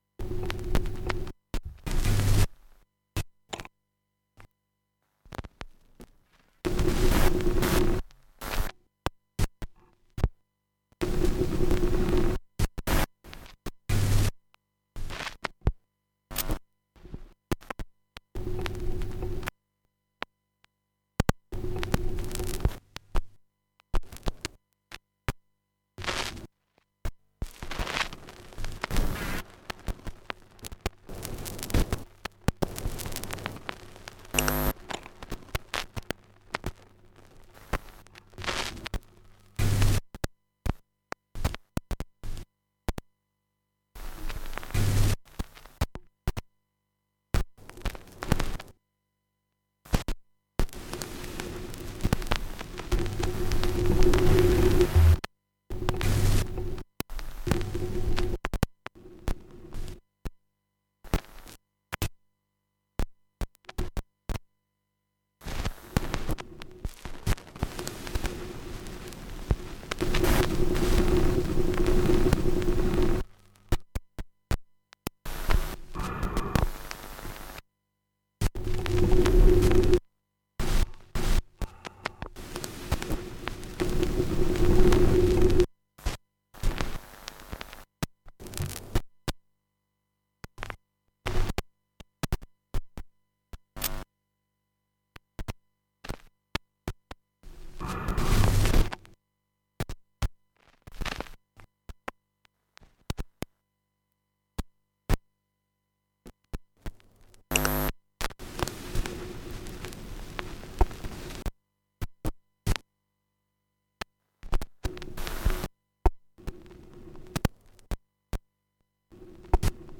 Samples, Vocoder, Sherman Filterbank
9 Flashplayer spielen die gleichen Samples wie beim Konzert.